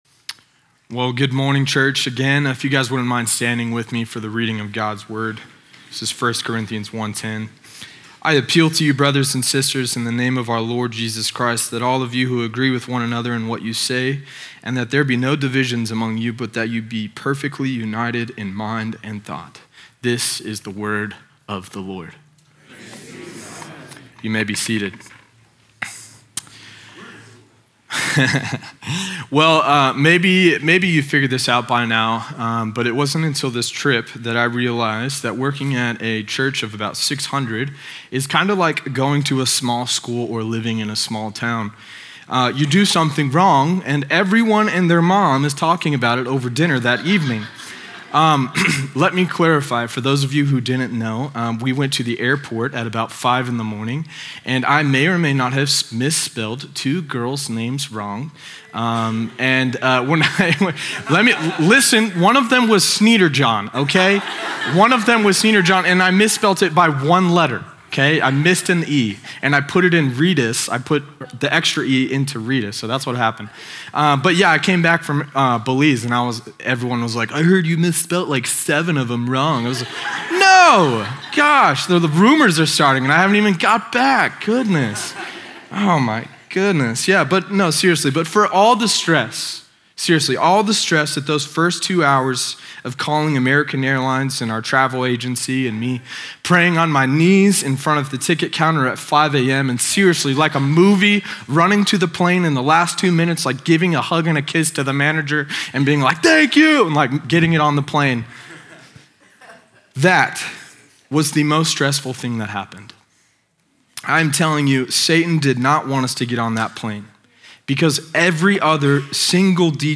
This week, our Belize Mission Team is leading the entire service! Hear powerful stories, see moments from the trip, and discover how God moved in and through the team.
sermon audio 0629.mp3